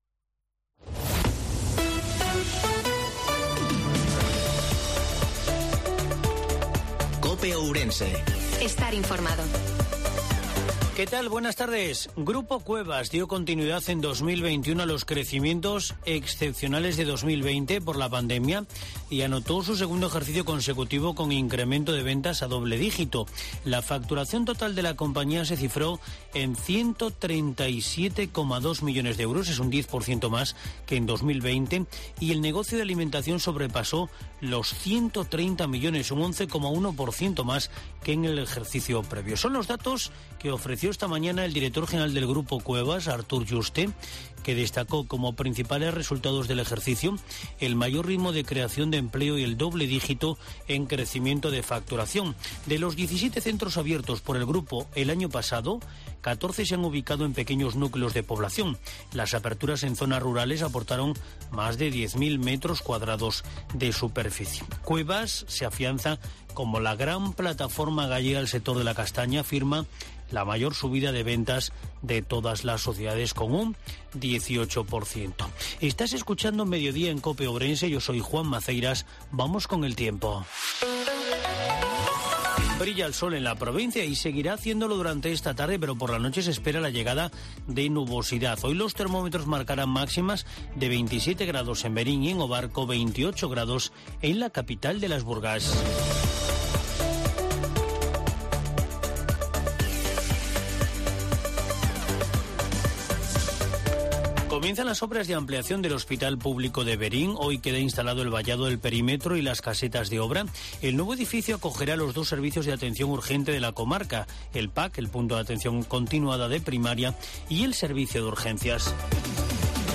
INFORMATIVO MEDIODIA COPE OURENSE 28 JUNIO 2022